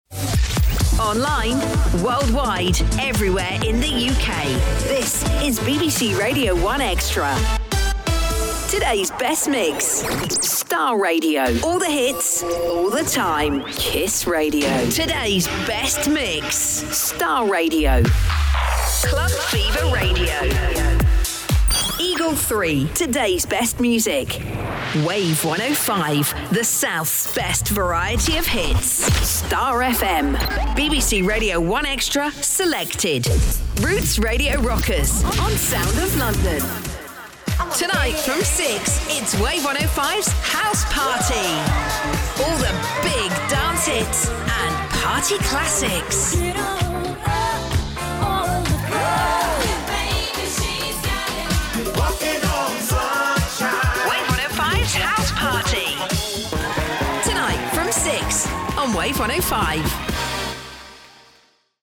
Englisch (Britisch)
Radiobildgebung
Hoch